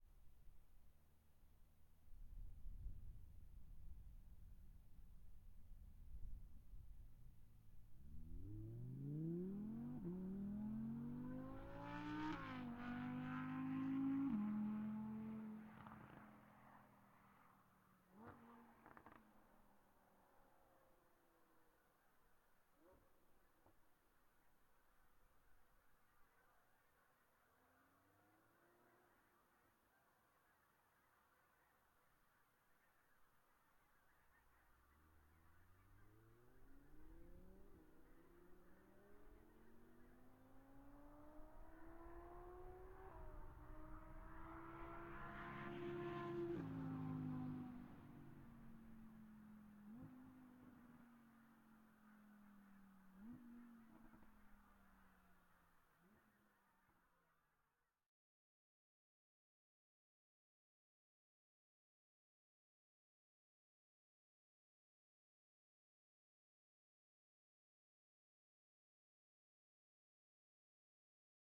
Audi, R8, t11, Ext, Fast, Various, Distant, LS12.ogg